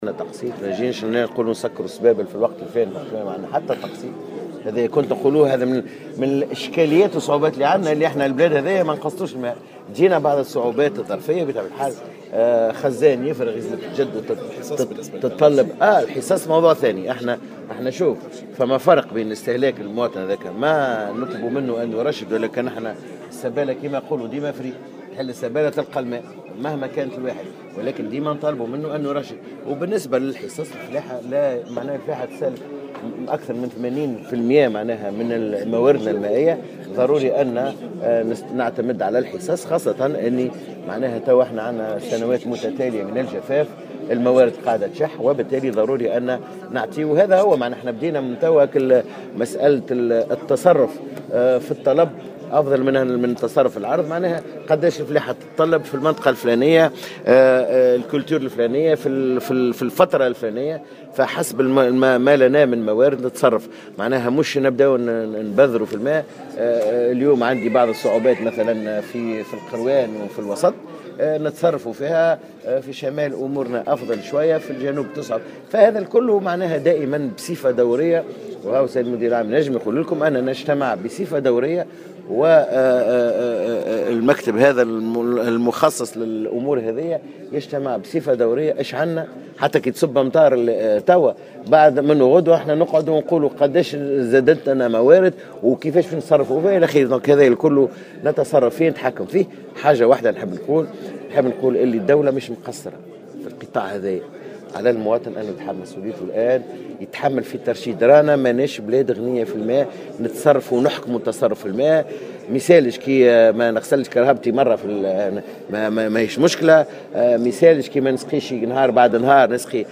أكد وزير الفلاحة سمير بالطيب في تصريح لمراسلة الجوهرة "اف ام" اليوم الأربعاء أن تونس دولة مواردها المائية شحيحة وهو ما يحتم عليها انتهاج سياسة واضحة في مجال ترشيد استهلاك المياه.